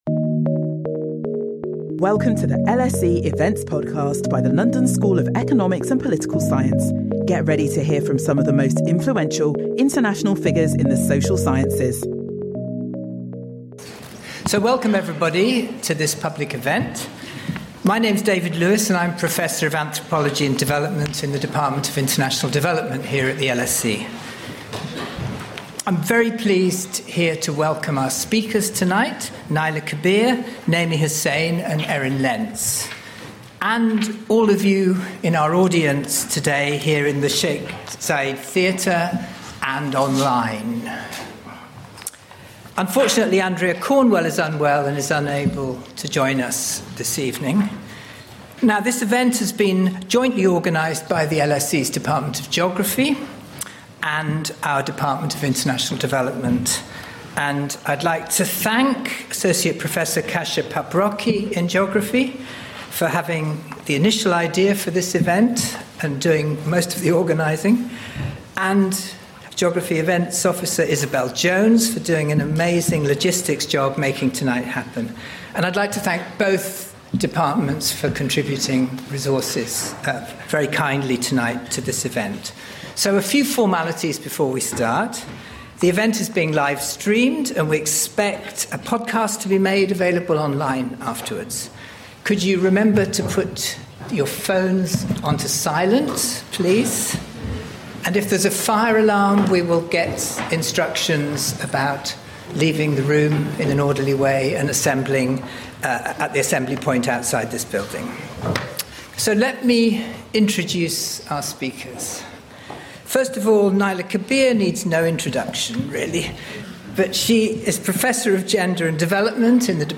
This inaugural lecture will look at key issues in the study of women’s health through the lens of reproductive histories, looking at both contingent and cumulated events to include physical and mental shocks such as conflict and disasters which would eventually have an impact later in life.